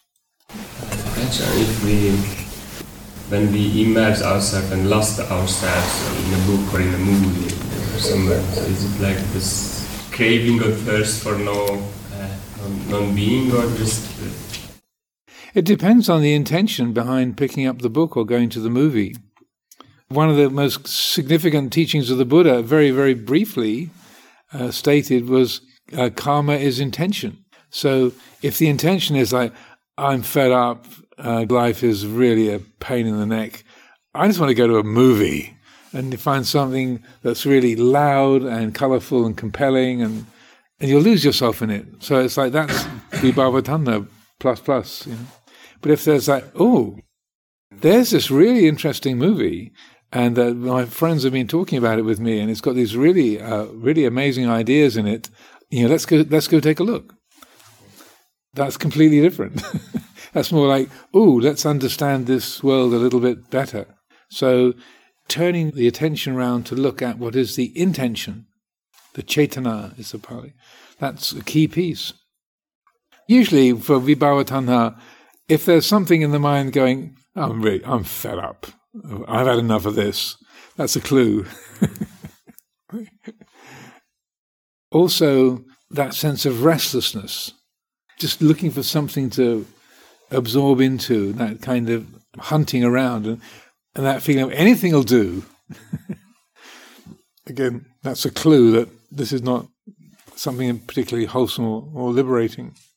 Answered by Ajahn Amaro.